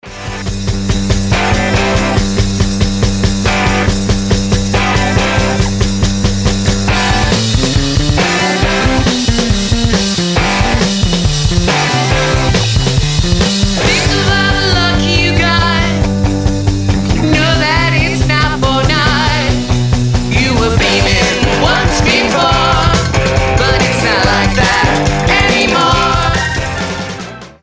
• Качество: 320, Stereo
indie rock
бодрые